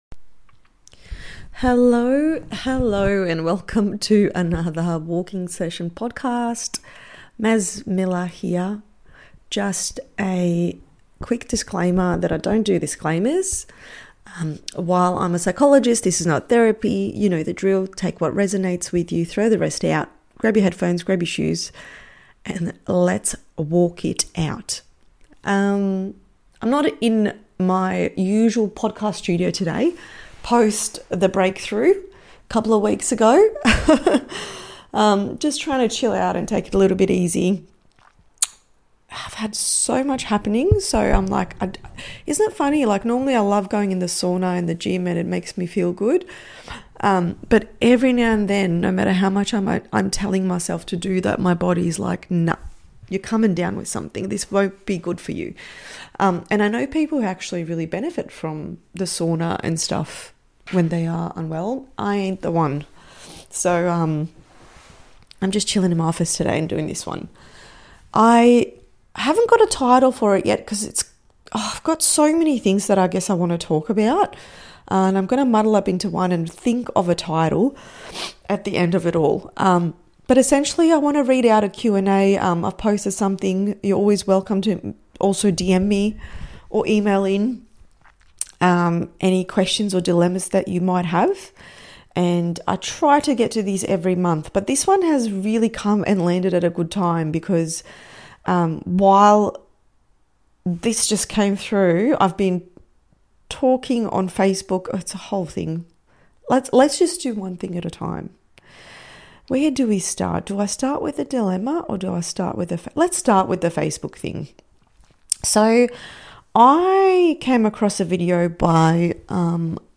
Not in my usual studio today (post-breakthrough vibes), just walking through a messy, honest chat about connection, isolation, and that viral advice to “just let them.”